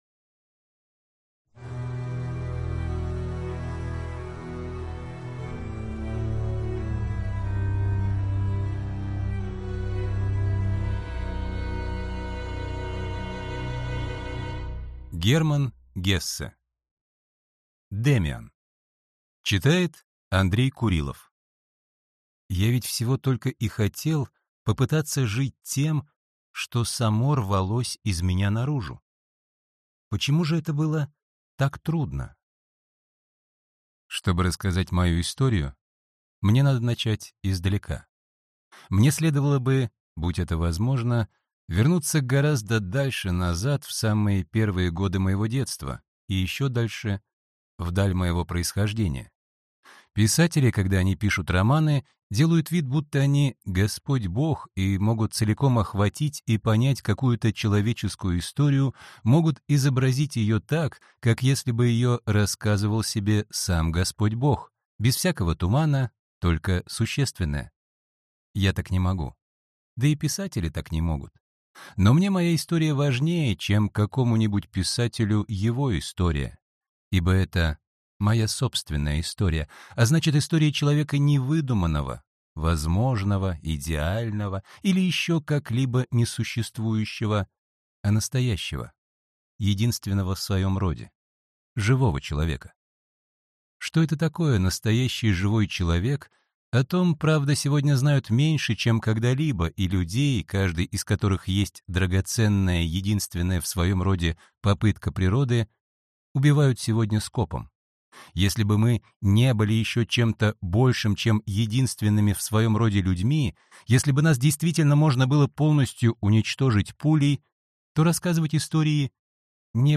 Аудиокнига Демиан | Библиотека аудиокниг